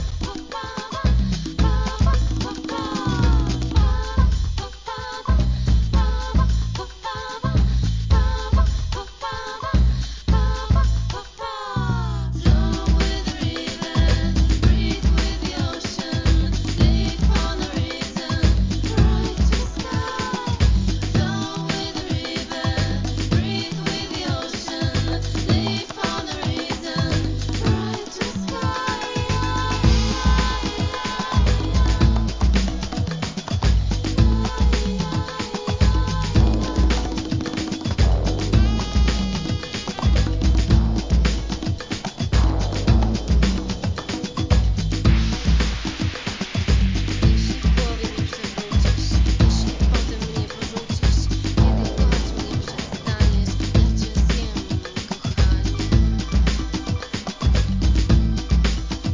HIP HOP/R&B
ブレイクビーツ